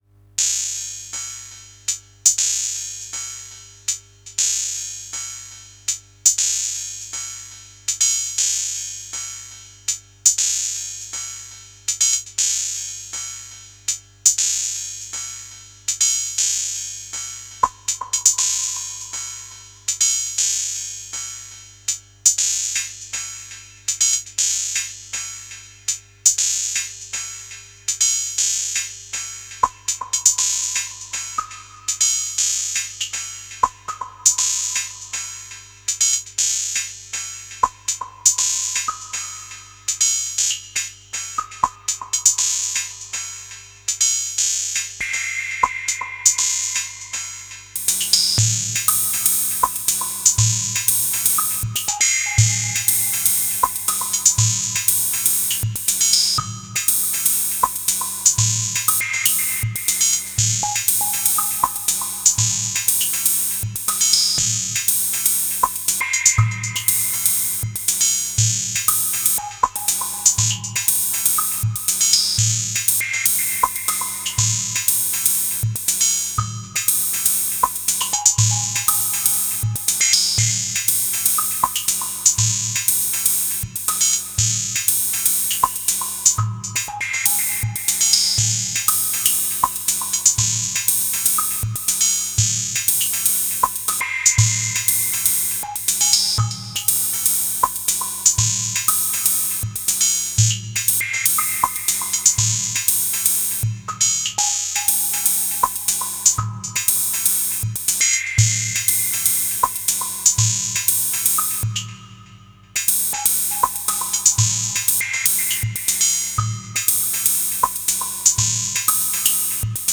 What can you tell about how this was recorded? An awful hum and a bit whatever anyways, must have been quite tired…